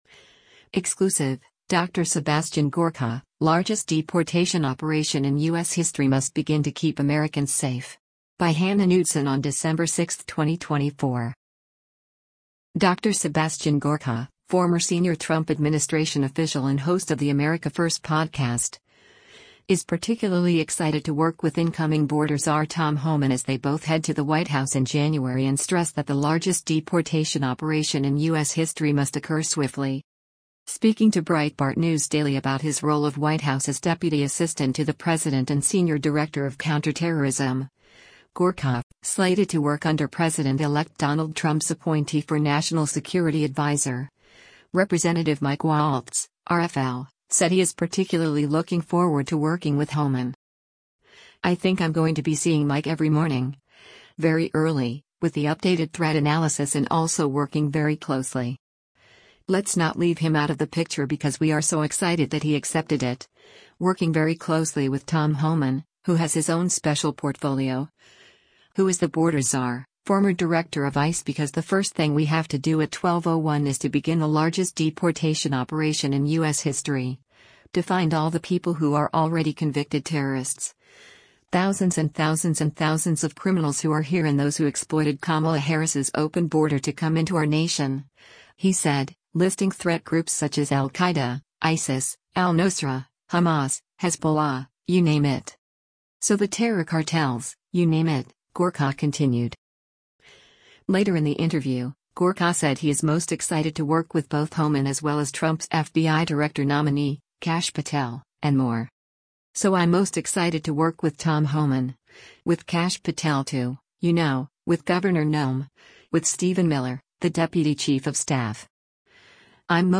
Speaking to Breitbart News Daily about his role of White House as deputy assistant to the president and senior director of counterterrorism, Gorka — slated to work under President-elect Donald Trump’s appointee for national security adviser, Rep. Mike Walz (R-FL) — said he is particularly looking forward to working with Homan.
Later in the interview, Gorka said he is most excited to work with both Homan as well as Trump’s FBI Director nominee, Kash Patel, and more.
Breitbart News Daily airs on SiriusXM Patriot 125 from 6:00 a.m. to 9:00 a.m. Eastern.